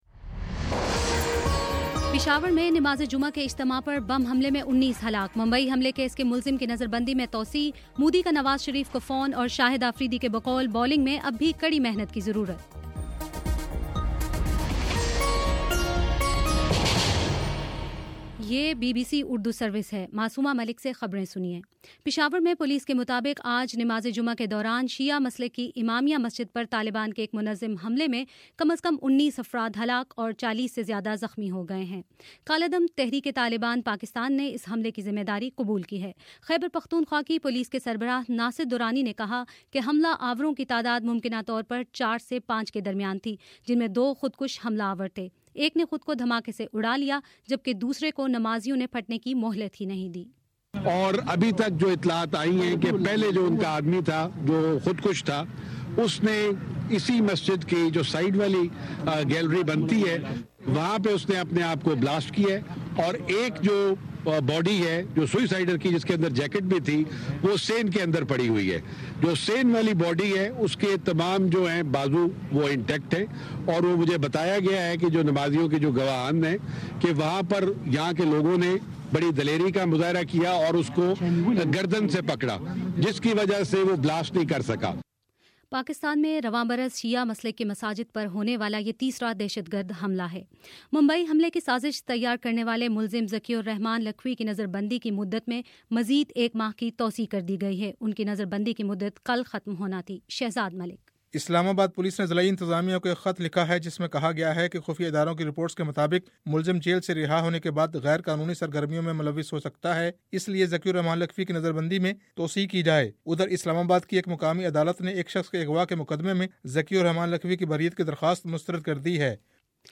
فروری13: شام سات بجے کا نیوز بُلیٹن